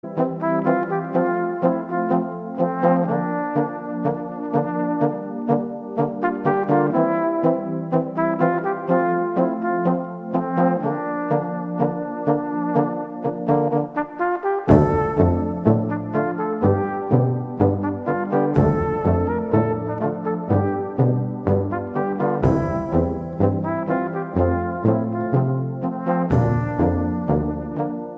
Gattung: variables Bläser-Ensemble
Besetzung: Ensemble gemischt
Bläserensemble mit Schlagzeug ad lib.
Tonart: d-moll.